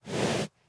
breathe1.mp3